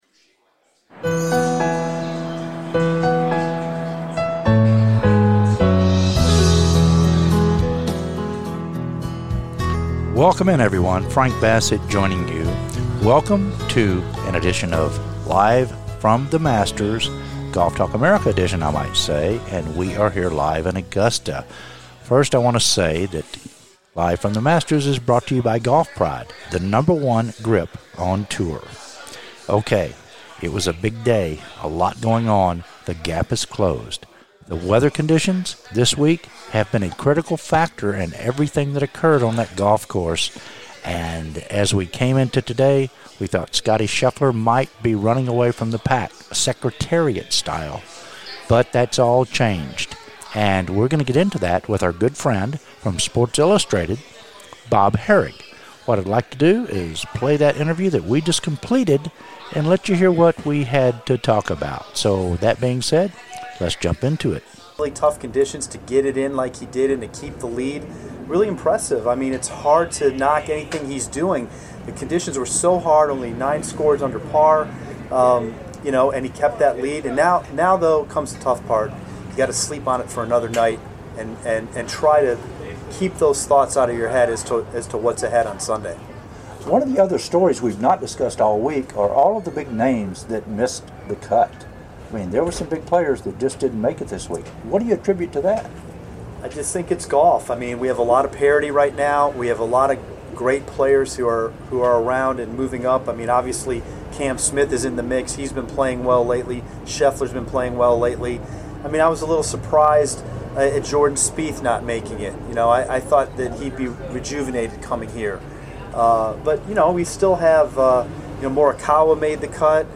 "LIVE" FROM THE MASTERS